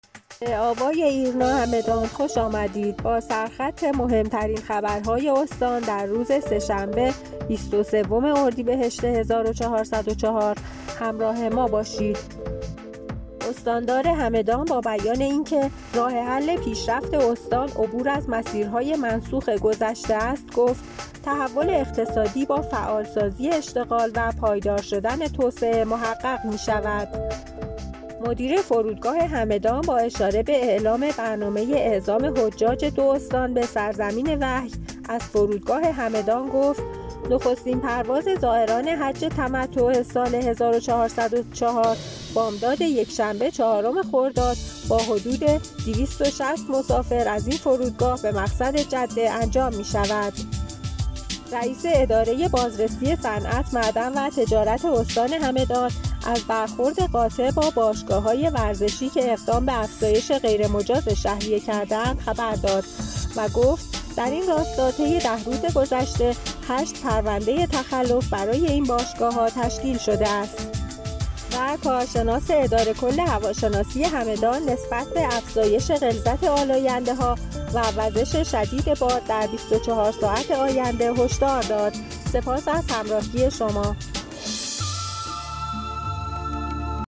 همدان-ایرنا- مهم‌ترین عناوین خبری دیار هگمتانه را هر شب از بسته خبر صوتی آوای ایرنا همدان دنبال کنید.